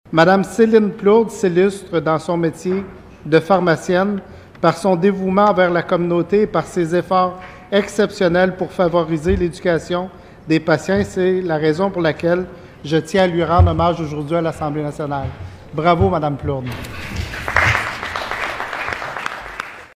Écoutez un extrait de la déclaration du député …